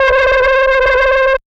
CHAD_HUGO_synth_one_shot_screech.wav